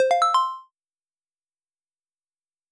menu_theme_unlock.wav